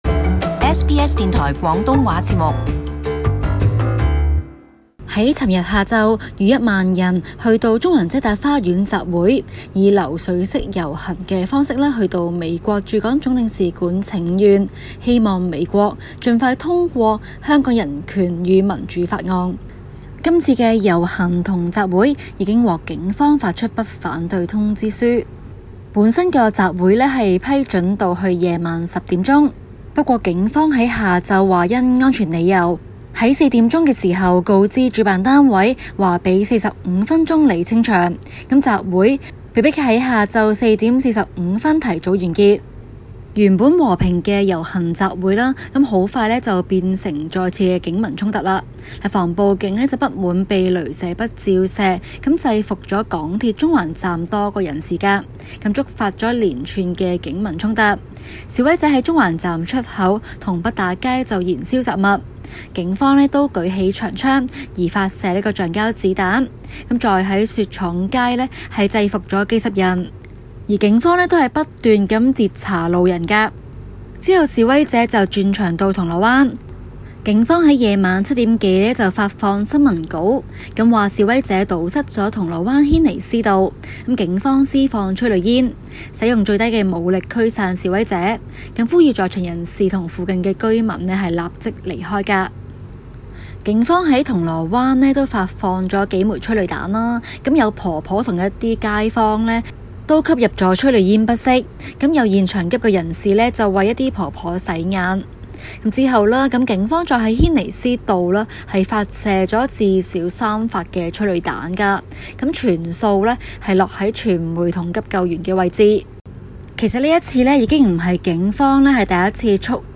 【中港快訊】遮打花園遊行後又發生警民衝突 06:14 Thousands of Hong Kong demonstrators marched to the U.S. Consulate on Sunday, urging President Donald Trump to help "liberate" their city. Source: AP SBS廣東話節目 View Podcast Series Follow and Subscribe Apple Podcasts YouTube Spotify Download (11.42MB) Download the SBS Audio app Available on iOS and Android 雖然林鄭月娥已宣佈撤回修例，可是示威活動依舊。